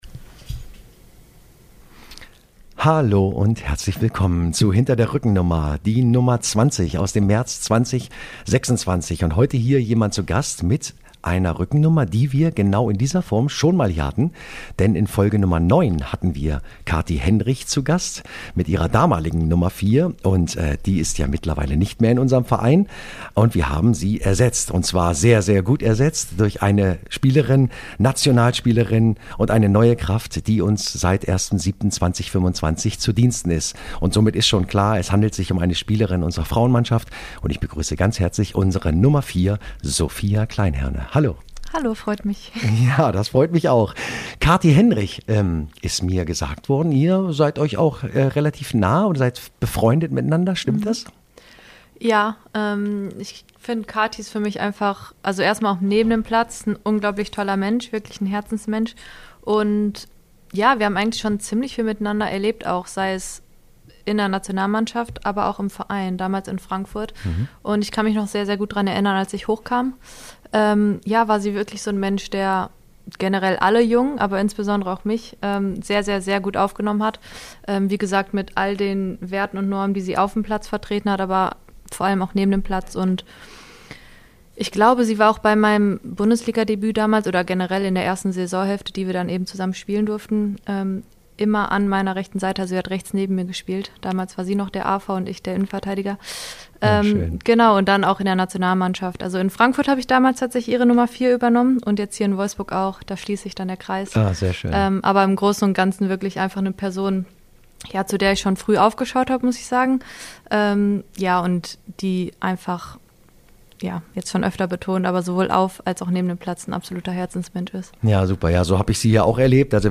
Beschreibung vor 1 Woche In der 20. Ausgabe unseres Podcast-Formats „Hinter der Rückennummer“ erzählt unsere Nationalspielerin Sophia Kleinherne unter anderem warum es für sie von Vorteil war in frühen Jahren oft die Jüngste gewesen zu sein, wie ein kleines Geschenk zu ihrem steten Begleiter wurde und wie es sich angefühlt hat, ihr Debüt für die Nationalmannschaft in einem der größten und berühmtesten Stadion der Welt spielen zu dürfen.